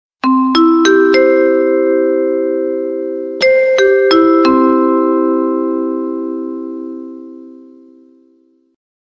Flughafen Ansage Sound klingelton kostenlos
Kategorien: Soundeffekte